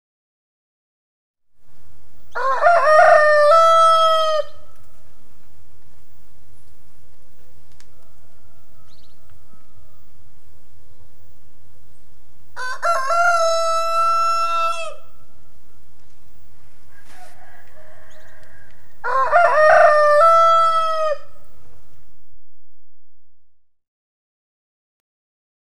Звуки петуха
Петух громко кукарекает